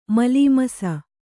♪ malīmasa